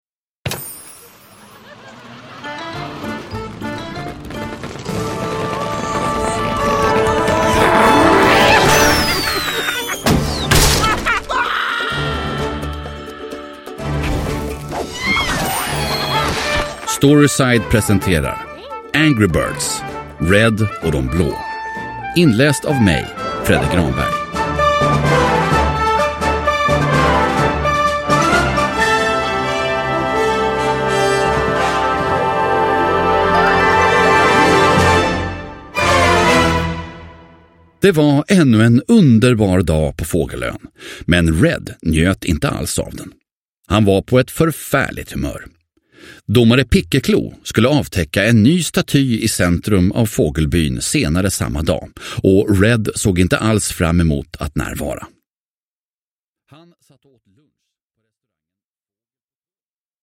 Angry Birds - Red och De Blå – Ljudbok – Laddas ner